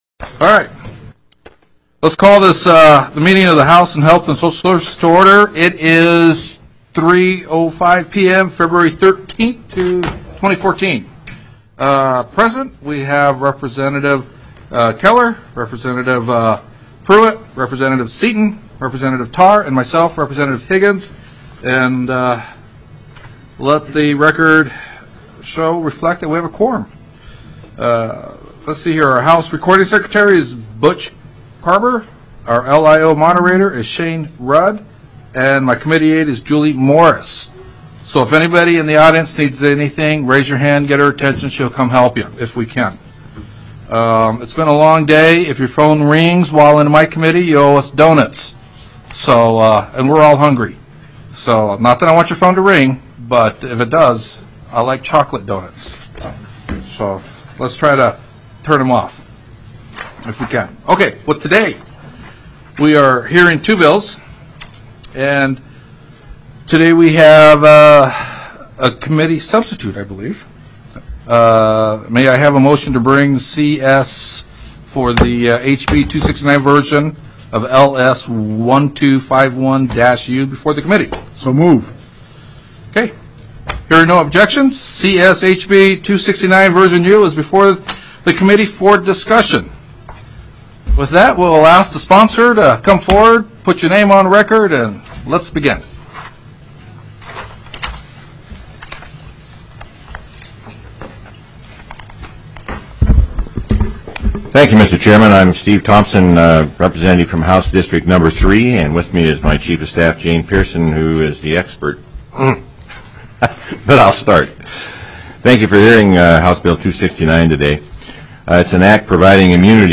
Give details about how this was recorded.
02/13/2014 03:00 PM House HEALTH & SOCIAL SERVICES